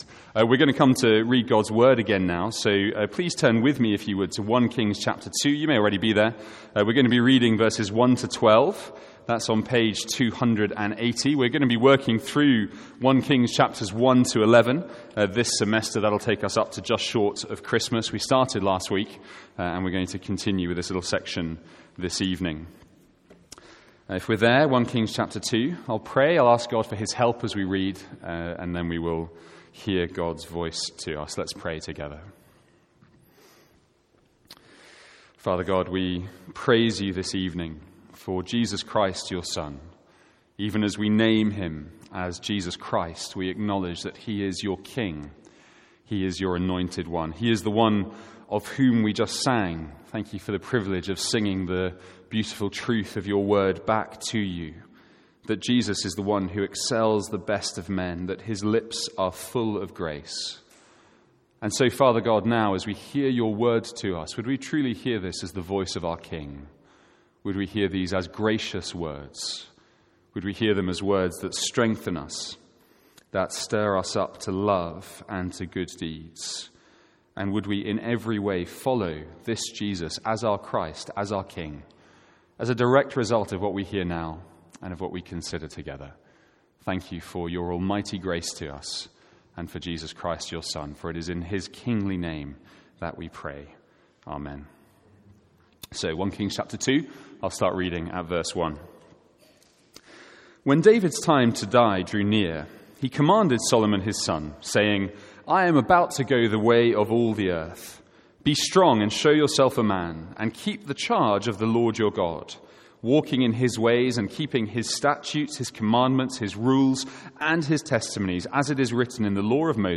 From our evening series in 1 Kings.